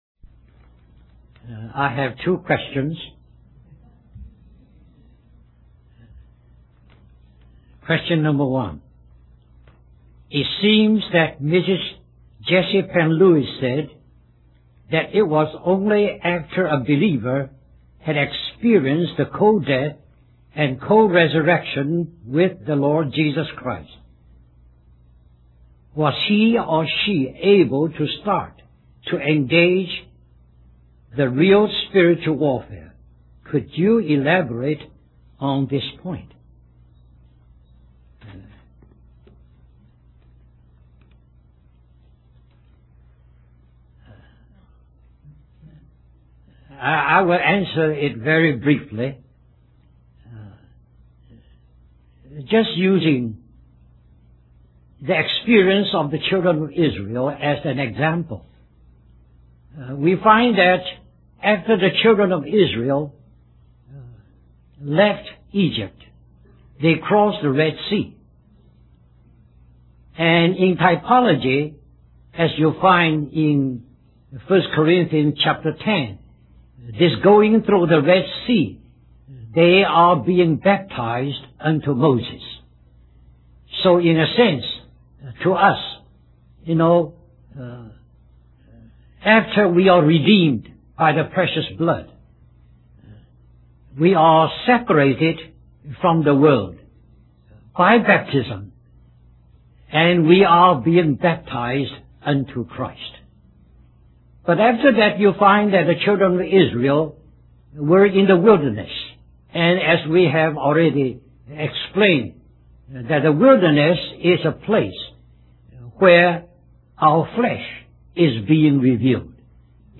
Spiritual Warfare: Questions and Answers Various October 8th, 2001 Harvey Cedars Conference Stream or download mp3 Summary The question and answer session ran longer than the tape that it was originally recorded on. Therefore, the recording of the session presented here is missing the last portion of the session.